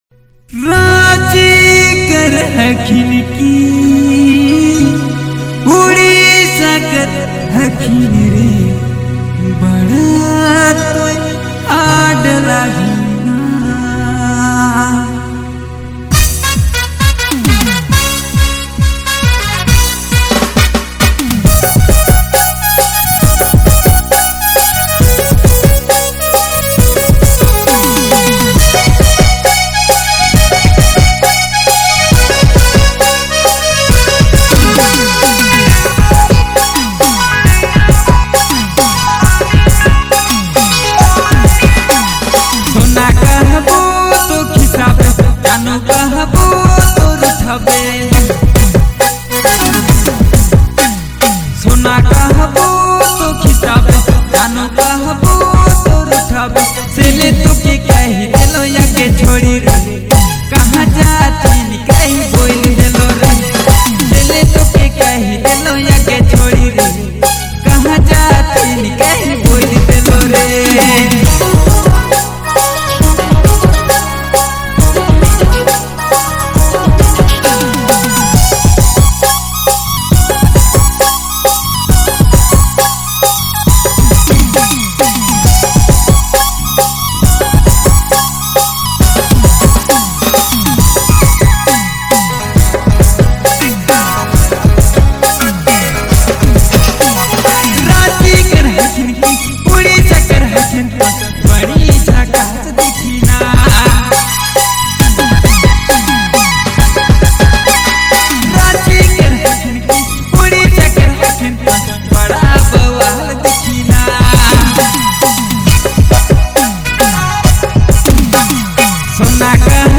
vibrant Nagpuri dance track
featuring the energetic vocals